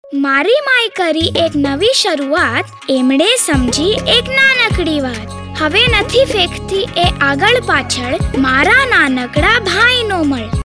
Radio spot Hindi TSC child excreta disposal pit children